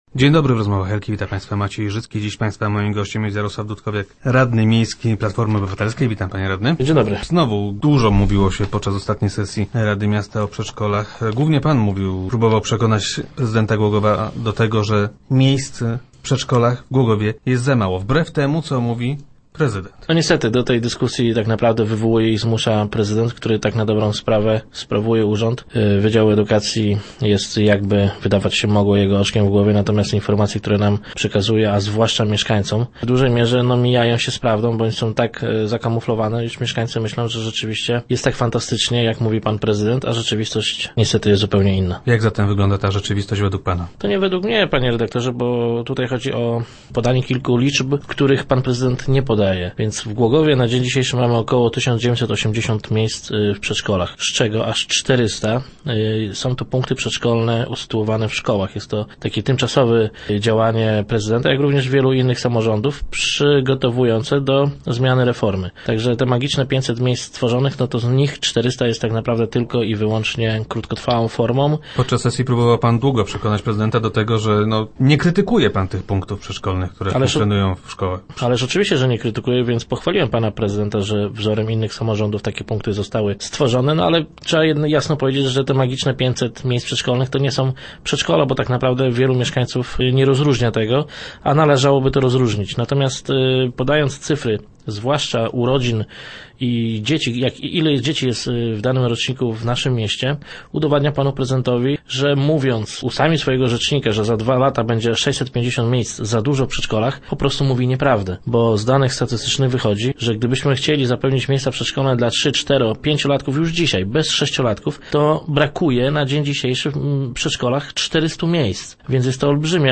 0327_dudkowiak_do_rozmow.jpgOpozycja nie ustępuje w walce o miejsca w przedszkolach. – Wbrew temu co mówi prezydent Głogowa, jest ich o wiele za mało – twierdzi Jarosław Dudkowiak, radny miejski Platformy Obywatelskiej, który był gościem Rozmów Elki.